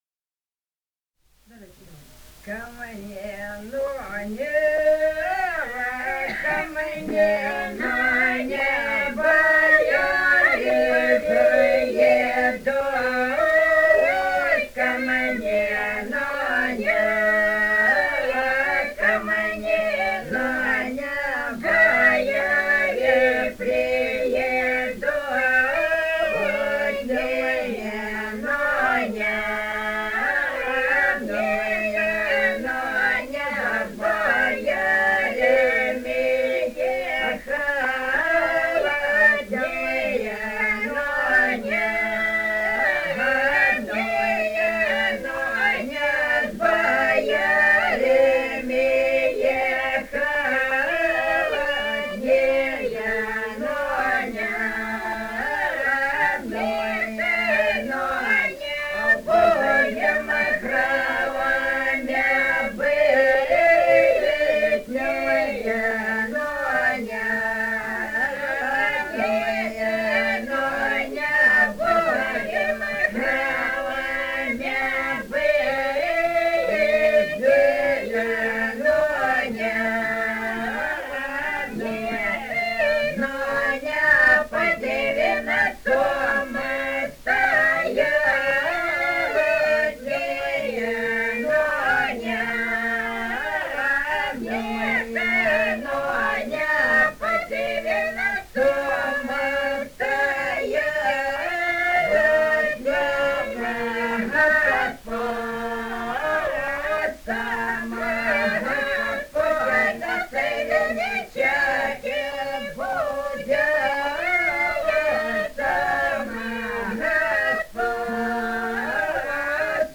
полевые материалы
Ростовская область, г. Белая Калитва, 1966 г. И0942-07